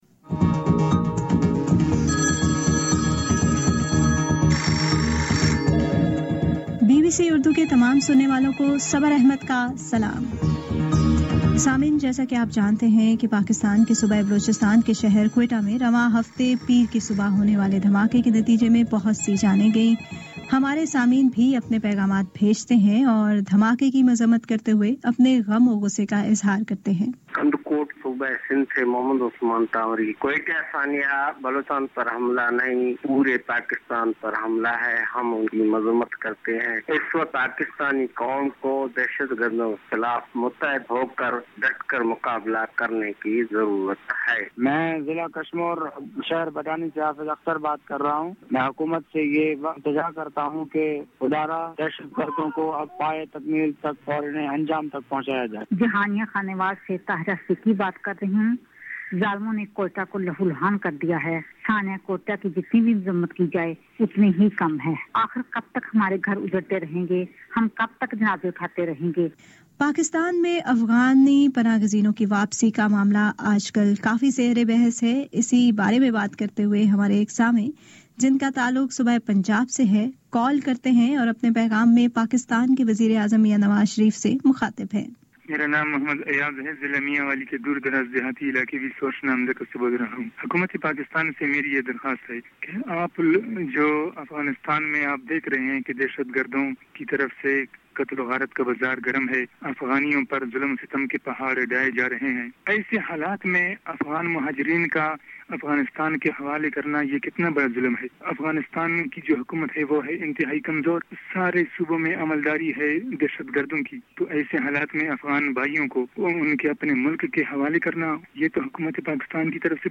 اپنی رائے کا اظہار کرنے کے لیے آپ پاکستان کے کسی بھی علاقے سے ہمیں مفت فون کر سکتے ہیں۔